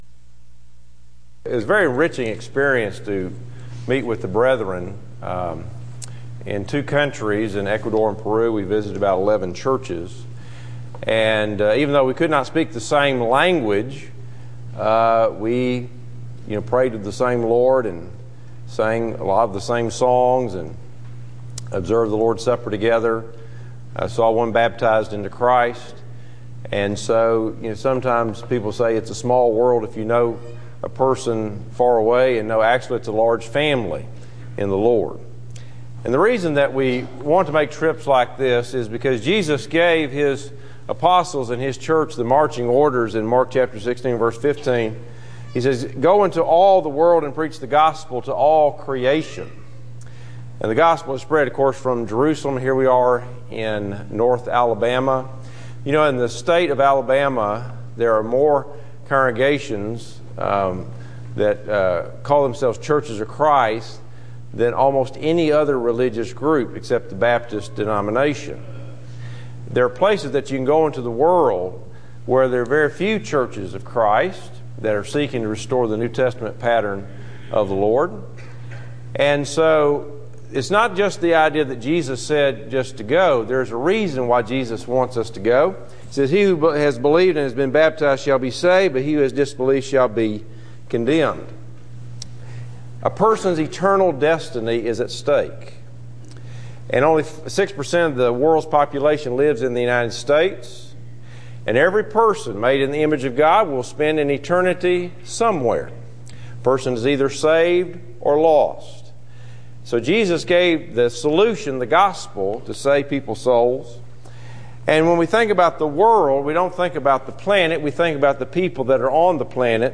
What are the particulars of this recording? Service: Sun AM Type: Sermon